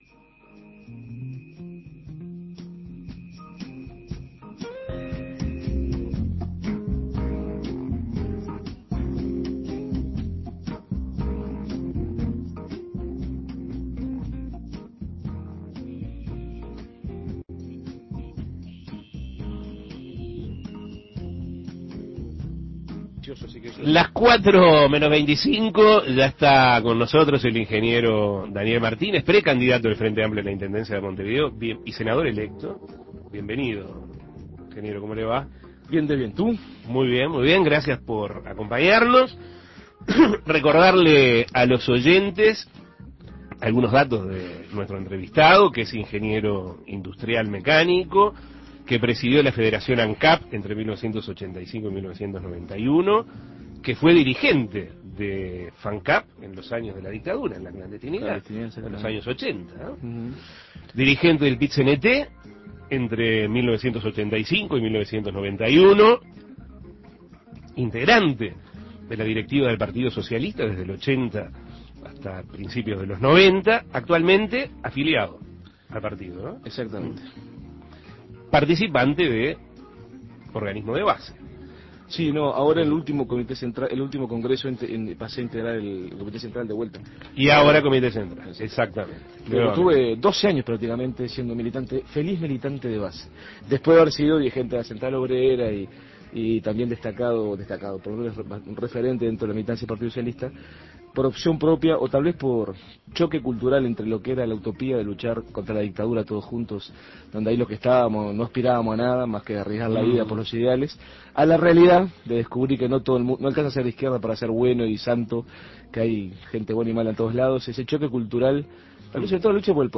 El ingeniero Daniel Martínez, ministro de Industria y presidente de Ancap de la Administración de Tabaré Vázquez, dialogó sobre la propuesta del Partido Socialista de cara a esta instancia electoral, sobre su precandidatura a la jefatura comunal y sobre mejoras para la ciudad, junto con la resolución de algunos inconvenientes. Escuche la entrevista.